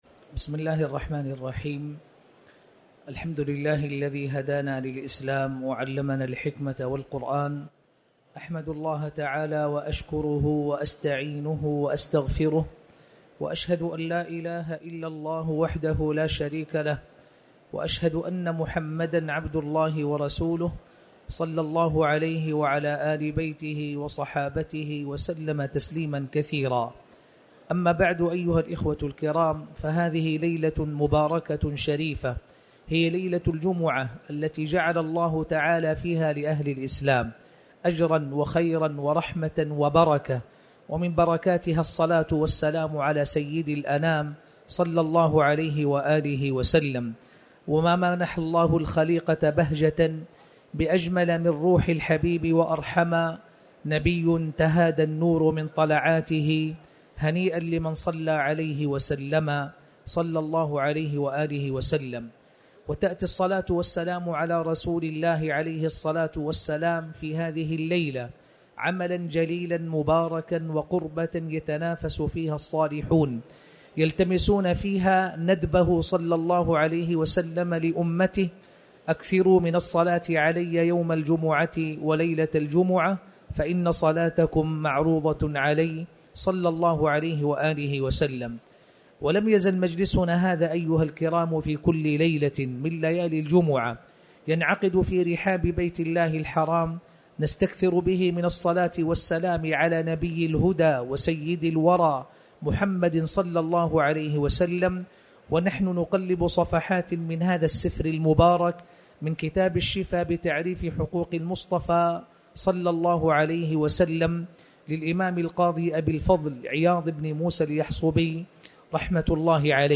تاريخ النشر ١٠ شوال ١٤٤٠ هـ المكان: المسجد الحرام الشيخ